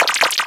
Cri d'Araqua dans Pokémon Soleil et Lune.